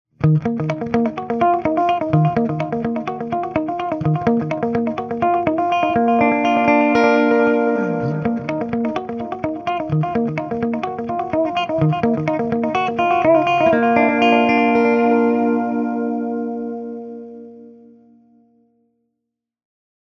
A lovely, warm electronic delay machine designed around the PT2399 echo processor. Approx. 450mS max echo time, buffered/trails bypass and self-oscillation mania on demand.
Brighter repeats for rhythmic styles and conventional applications.